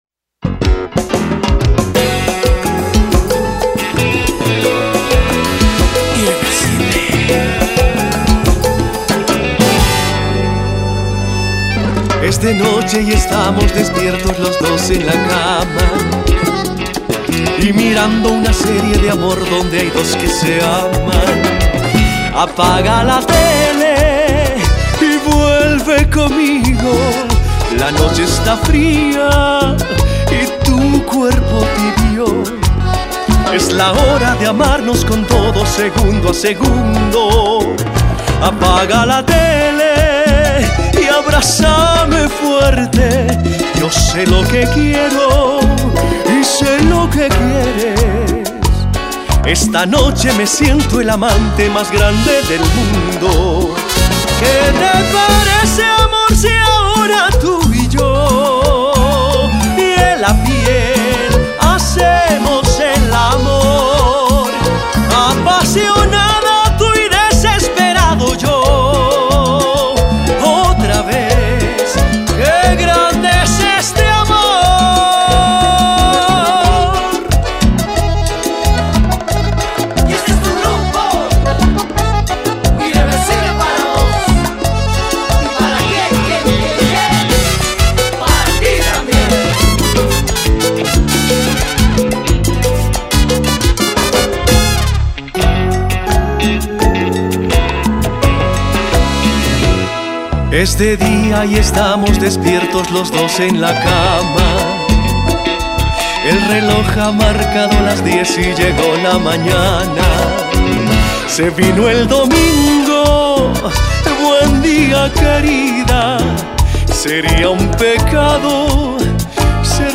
Cumbia Latina